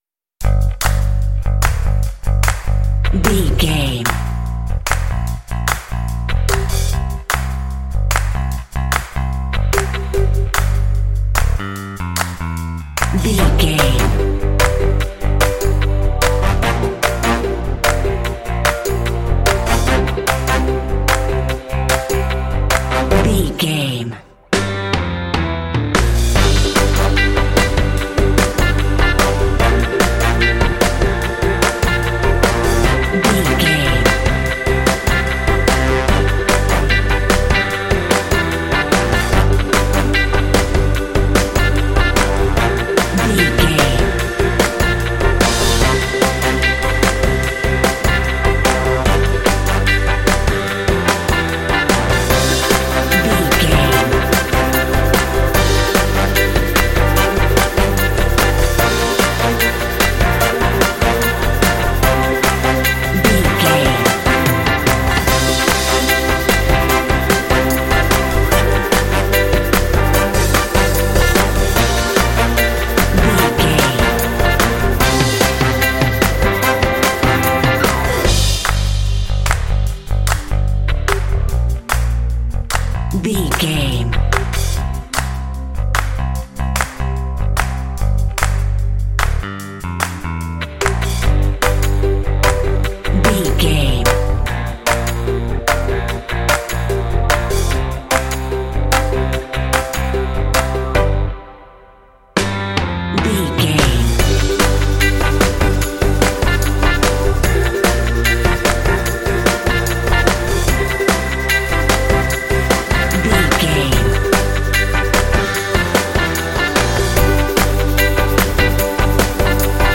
Aeolian/Minor
fun
bright
lively
sweet
brass
horns
electric organ
drums
bass guitar
modern jazz
pop